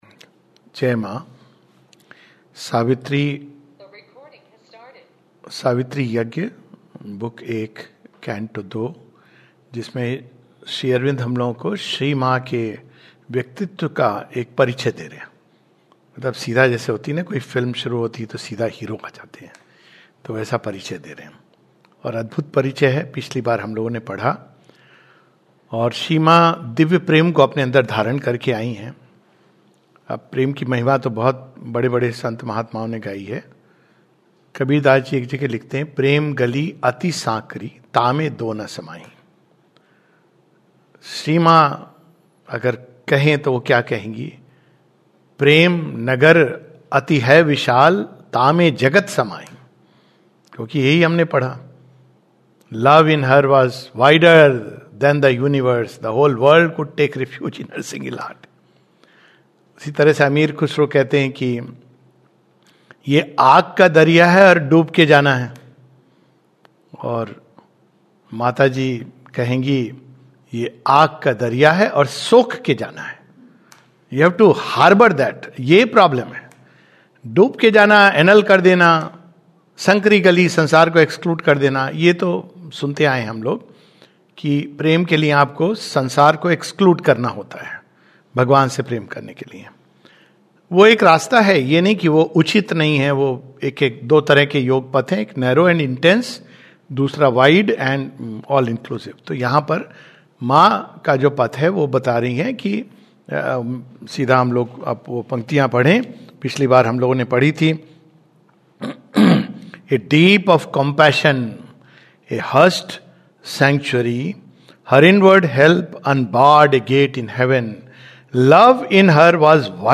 [The Divine Accepting our Mortality]. A talk